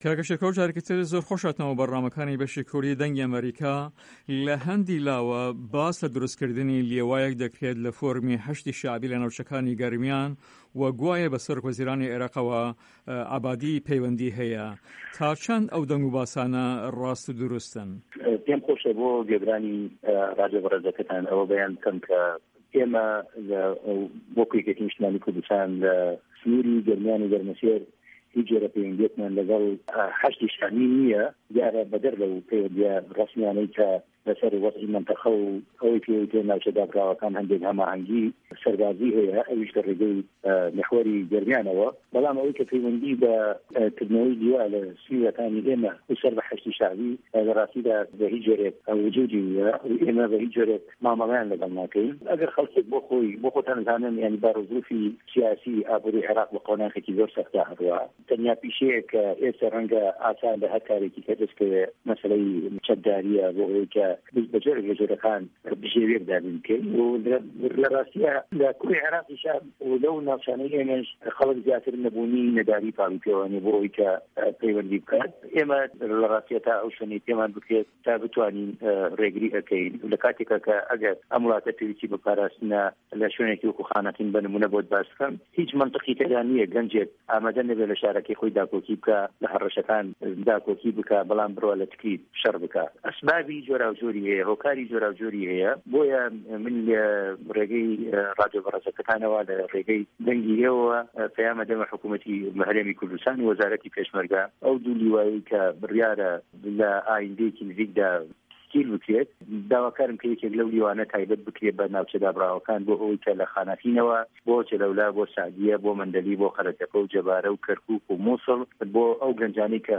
هه‌ڤپه‌یڤینێکدا له‌گه‌ڵ به‌شی کوردی ده‌نگی ئه‌مه‌ریکا